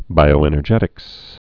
(bīō-ĕnər-jĕtĭks)